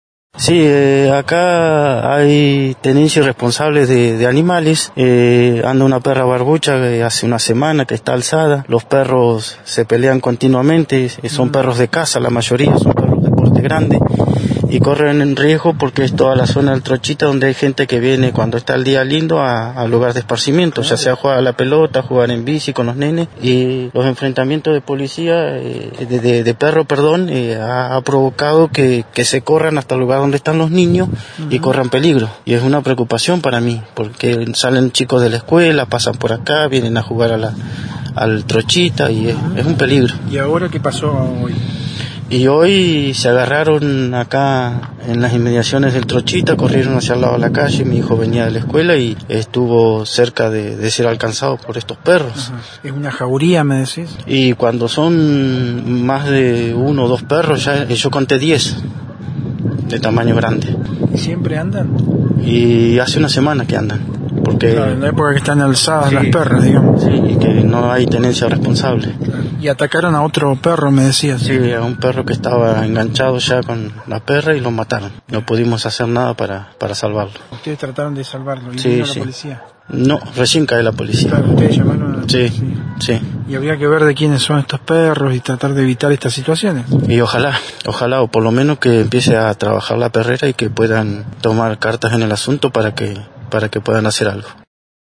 Escuchá el testimonio de un vecino que trató de intervenir ante el ataque de la jauría y relató a Noticias de Esquel lo sucedido.